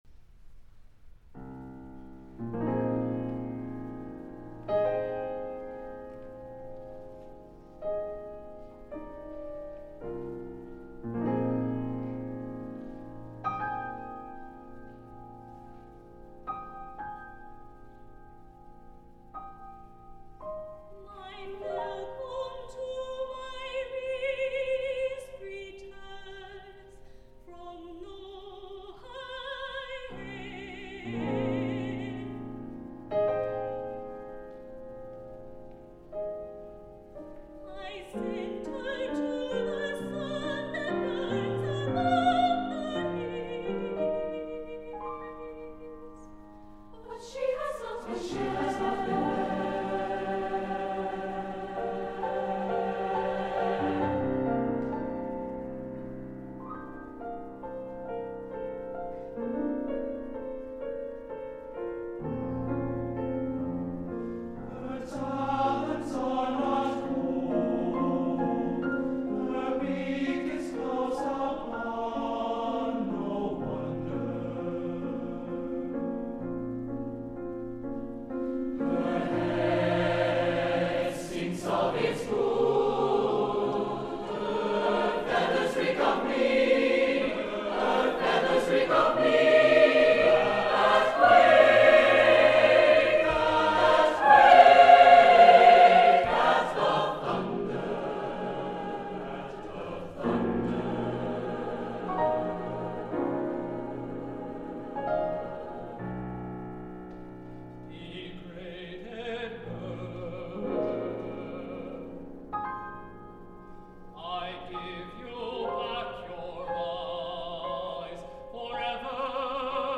A rapturous call to freedom and risk.
SSATB, piano